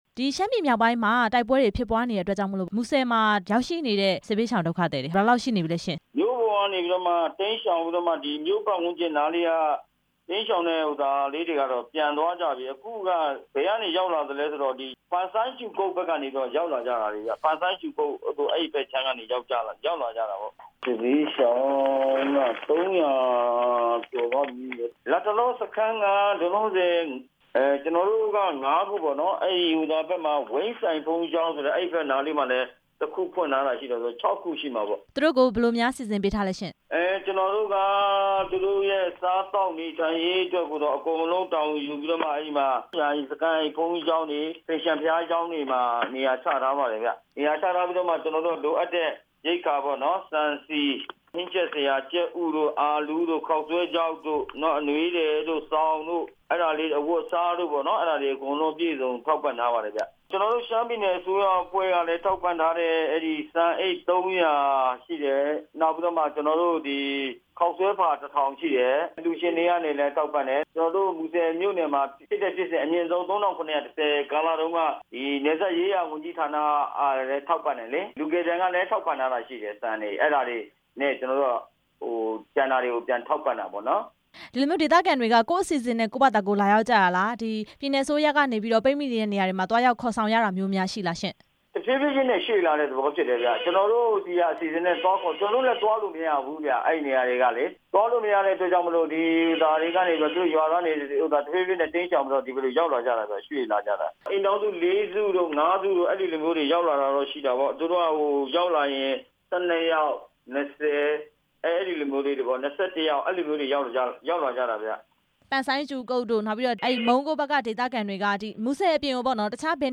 မူဆယ်ခရိုင်ဦးစီးမှူး ဦးဇော်မင်းနဲ့ မေးမြန်းချက်